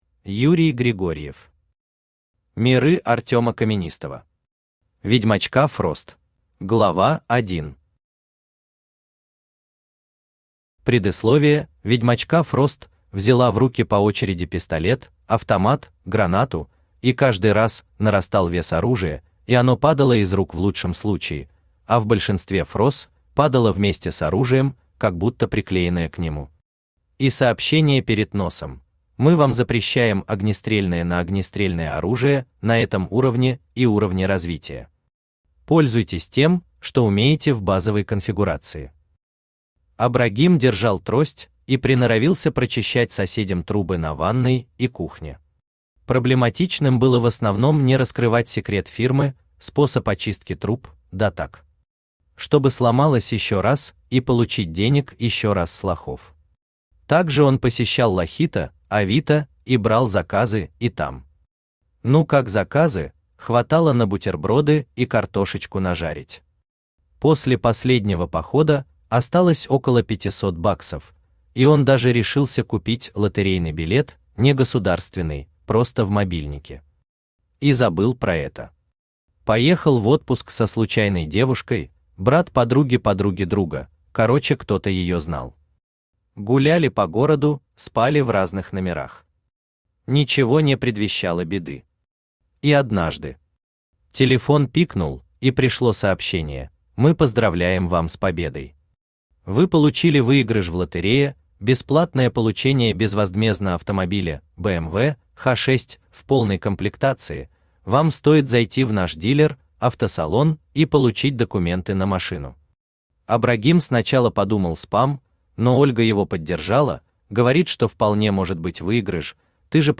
Аудиокнига Ведьмачка Фрост | Библиотека аудиокниг
Aудиокнига Ведьмачка Фрост Автор Юрий Григорьев Читает аудиокнигу Авточтец ЛитРес.